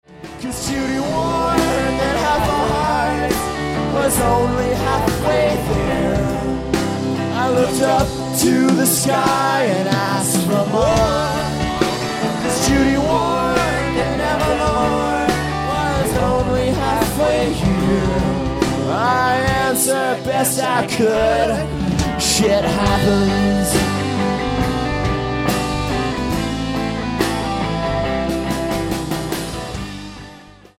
at Ultrasound Showbar in Toronto, Canada